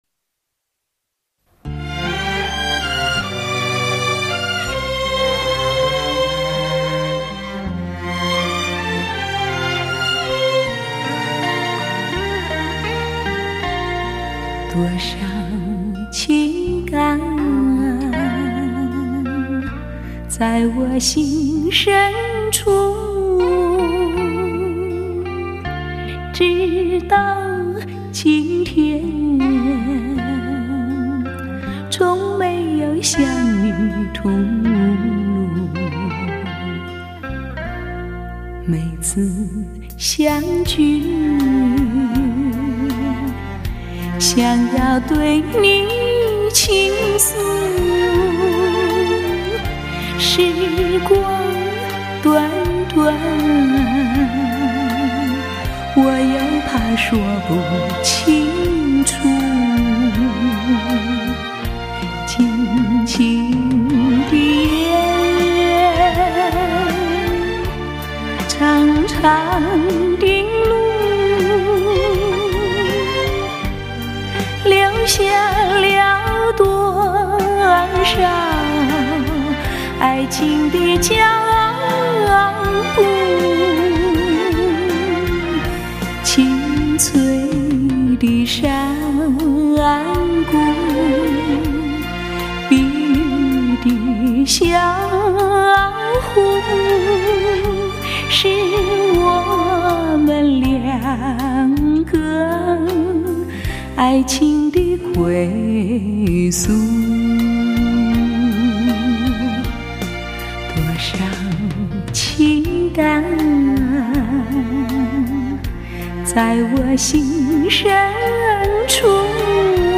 风花雪月的浪漫抵不过一场雨……美仑美奂的唱腔 “邓氏情歌”另添新曲。自然声效与极品人声相得益彰。
24Bit/96Khz的多轨数码录音，保证了音色的 清晰度和更宽阔的音域动态范围。